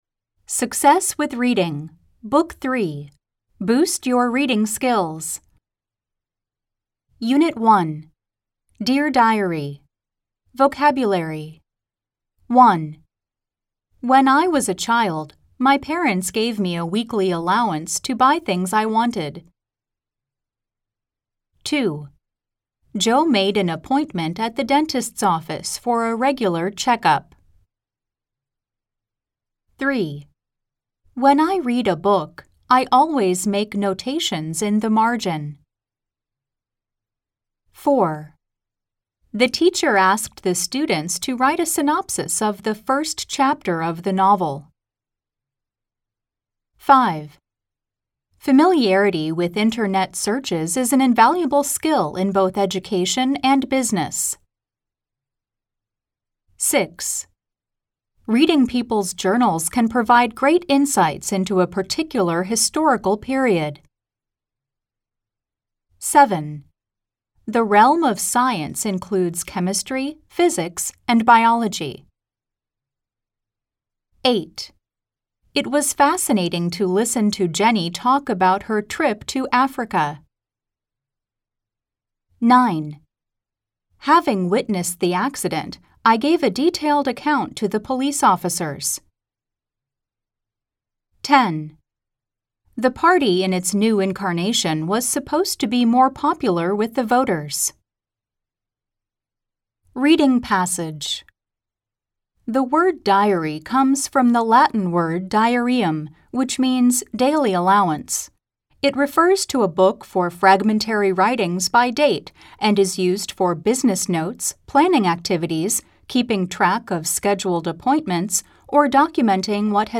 吹き込み Amer E